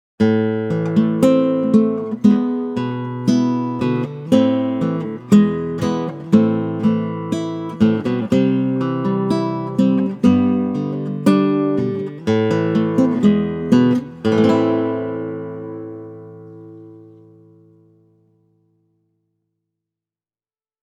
Esteve Jucarin ääni on iso ja selkeä, ja tarjolla on vaneripohjaiseksi klassiseksi kitaraksi yllättävän iso dynaaminen spektri. Alamidlessä on Jucarin soundissa pieni korostus, joka paksuntaa kitaran botnea oikein mukavalla tavalla.
esteve-organic-jucar.mp3